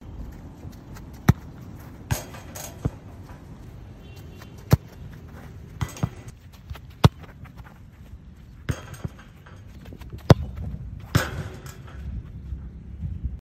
Tiếng Sút Bóng, Sút bóng vào gôn… khi đá bóng
Âm thanh Đá bóng, đá vào quả bóng… Tiếng Sút Bóng, phát bóng lên… khi đá bóng
Thể loại: Tiếng động
Description: Tiếng sút bóng vang lên khi cầu thủ dứt điểm, cú sút, đập bóng, đá phạt, kết thúc pha bóng, bắn phá khung thành.... âm thanh gồm tiếng gót giày chạm bóng, tần số gió, tiếng lưới rung khi bóng vào gôn.
tieng-sut-bong-sut-bong-vao-gon-khi-da-bong-www_tiengdong_com.mp3